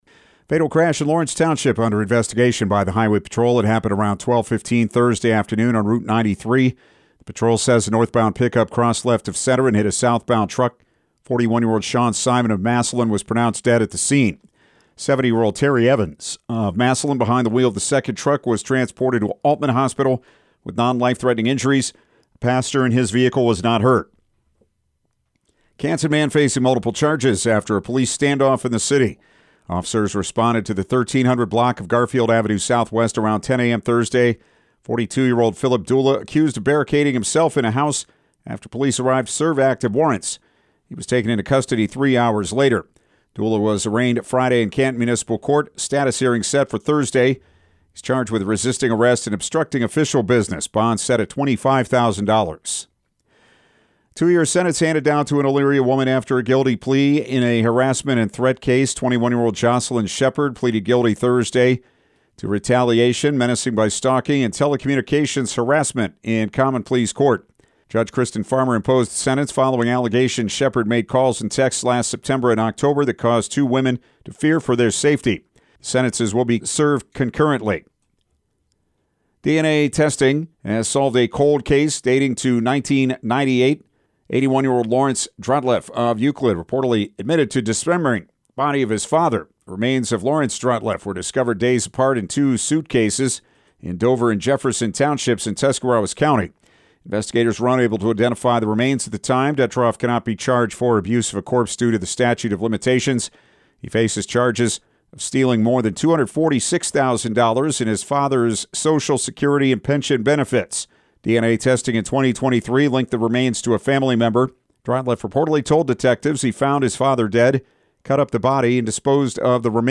Evening News
evening-news-4.25.mp3